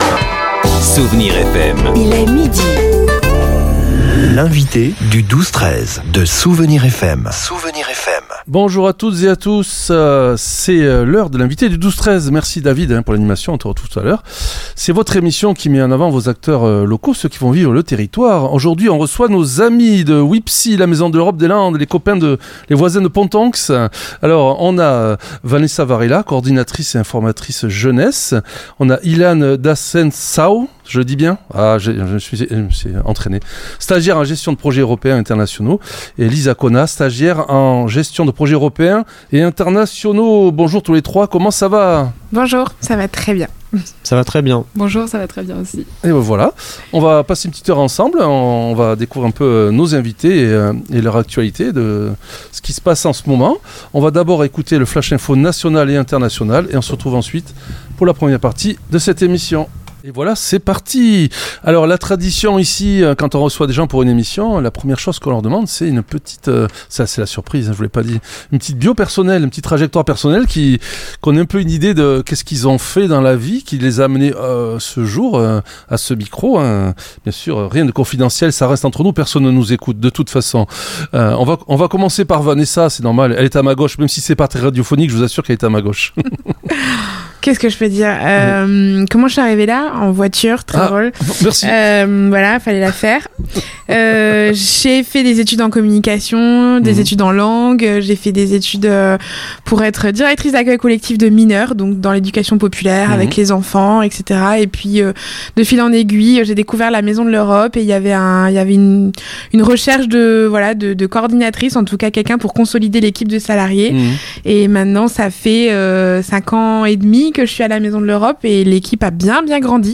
Véritable carrefour d'opportunités, l'association accompagne les jeunes dans leur insertion socio-professionnelle à travers des dispositifs concrets comme le « Labo à projets » ou le « Campus Volontariat ». L'entretien a mis en lumière la Cité des Langues qui propose des vacances linguistiques ludiques pour les 6-14 ans, transformant l'apprentissage en plaisir thématique à chaque période de vacances scolaires.